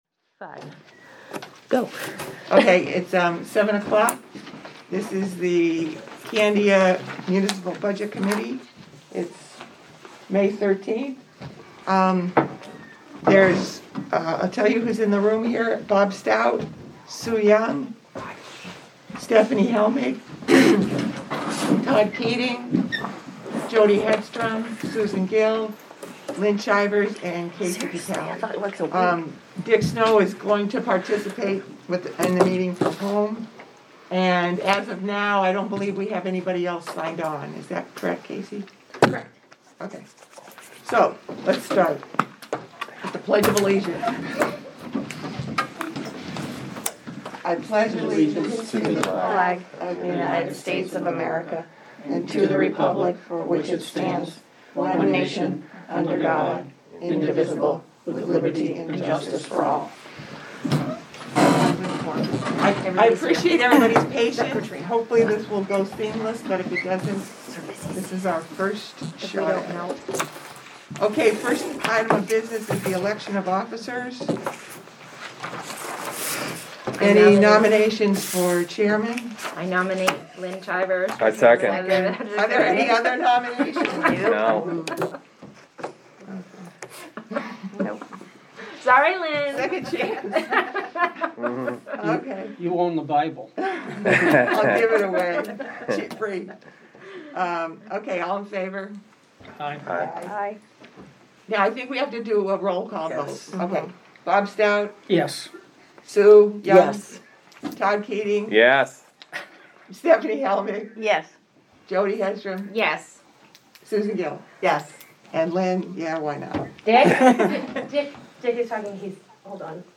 Audio recordings of committee and board meetings.
Budget Committee Meeting